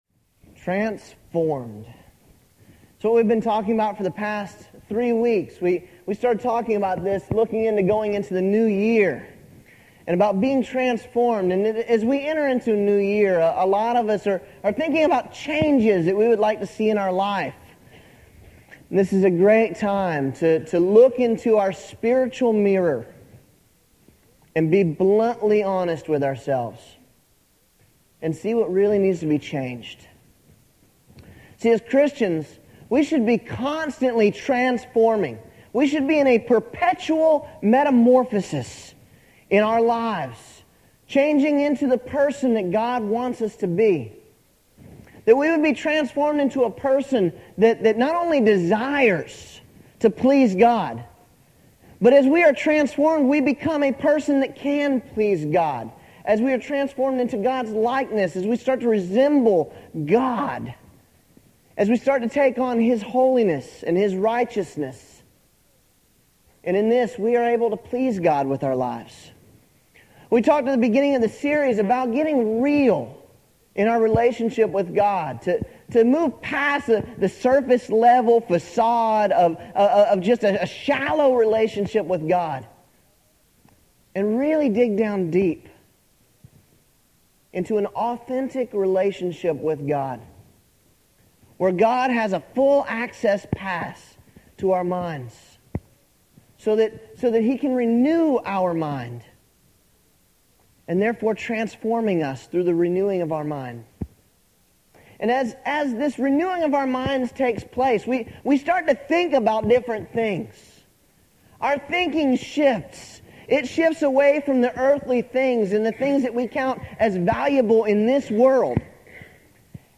When’s the last time you were “transformed” by God? December 30th – "Transform My Mind " Right Click to download this sermon January 6th – "Transform My Existence" Right Click to download this sermon January 13th – "Transform My Reflection" Right Click to download this sermon